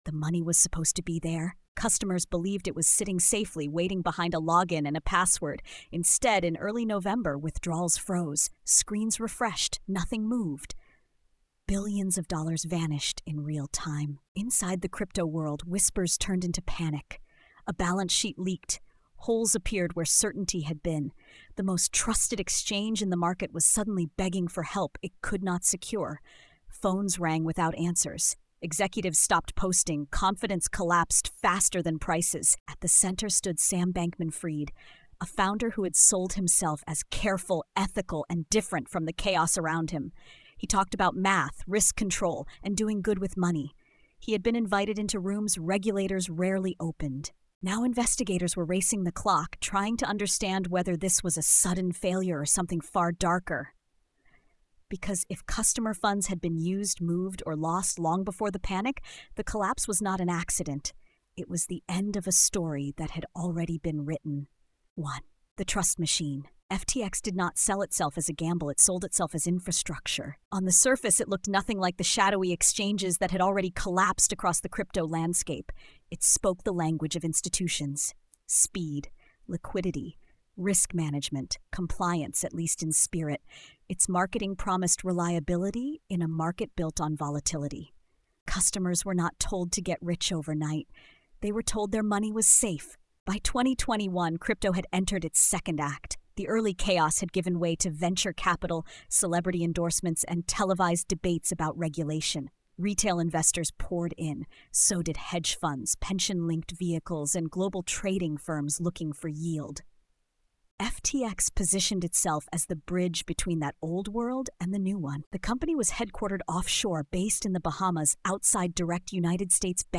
Unregulated Empire: FTX and Sam Bankman-Fried is a gritty investigative account of one of the largest financial collapses of the digital age. Told with a hard-edged, documentary tone, the series traces how a crypto exchange built on speed, trust, and charisma concealed deep structural risks while operating outside meaningful oversight. As markets turned and confidence cracked, the promise of safety unraveled, exposing alleged misuse of customer funds and a system that depended on belief more than boundaries.